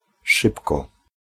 ʂ sz
szybko shore[4]